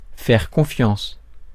Ääntäminen
Ääntäminen France: IPA: [fɛʁ kɔ̃.fjɑ̃s] Tuntematon aksentti: IPA: /fɛʁ kɔ̃.fjɑs̃/ Haettu sana löytyi näillä lähdekielillä: ranska Käännös 1. confiar Määritelmät Verbit Croire en quelqu'un ou en quelque chose, s'y fier .